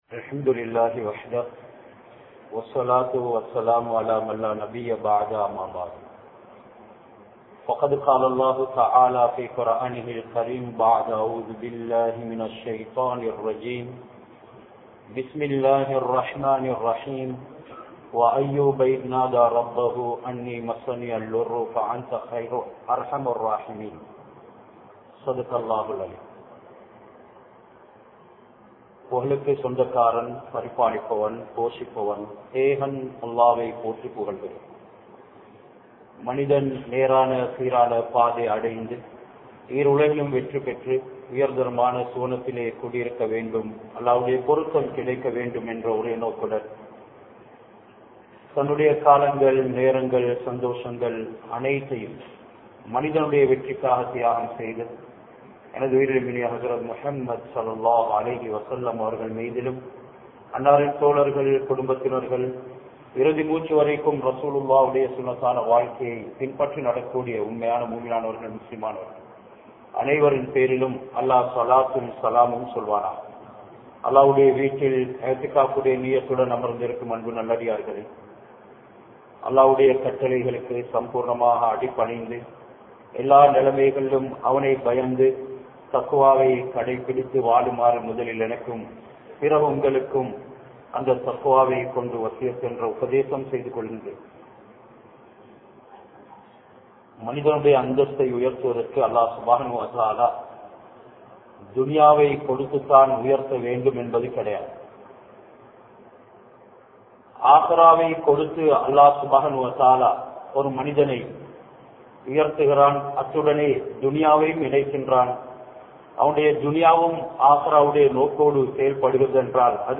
Nabi Ayyoob(Alai)Avarhalum Avarhalathu Manaivium (நபி ஐயூப்(அலை)அவர்களும் அவர்களது மனைவியும்) | Audio Bayans | All Ceylon Muslim Youth Community | Addalaichenai
Majmaulkareeb Jumuah Masjith